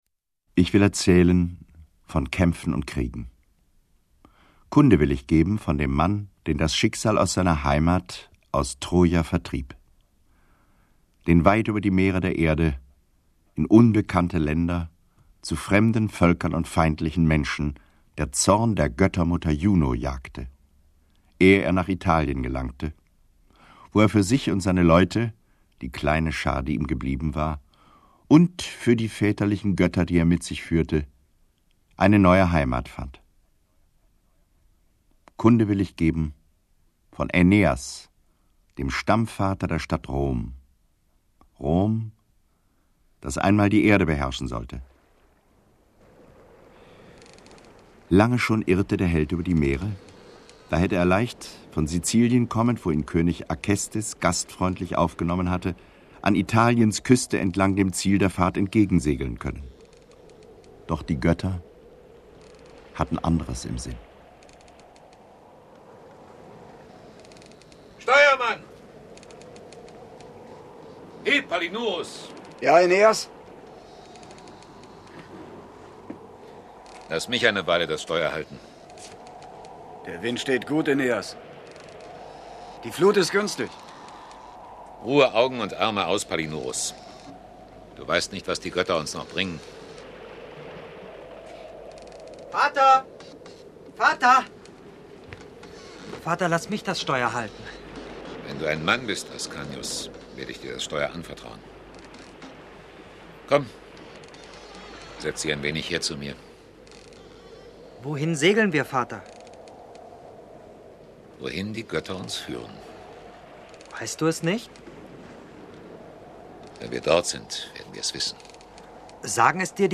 Hörspiel (3 CDs)
Die hochkarätig besetzte Hörspielinszenierung des SWR erweckt Vergils mythischen Bericht von der Entstehung Roms auf mitreißende Weise zu neuem Leben.